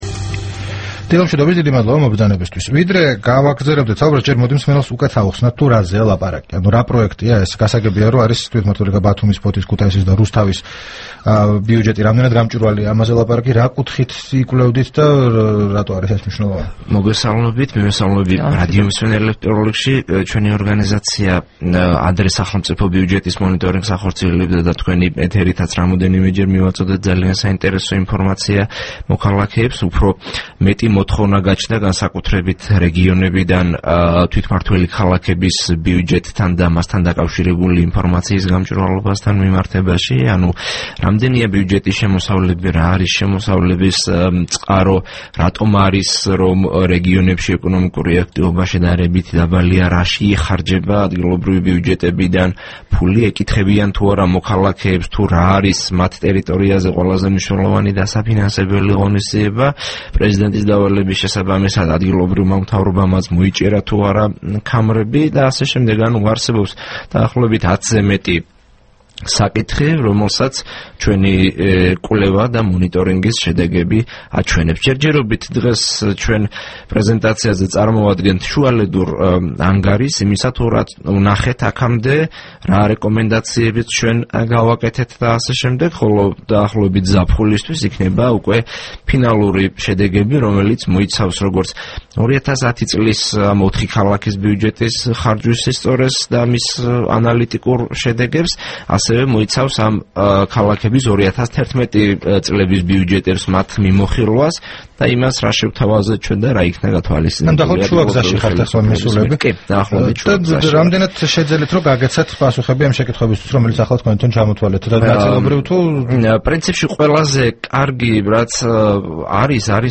მოისმინეთ: საუბარი დავით ნარმანიასთან